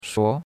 shuo2.mp3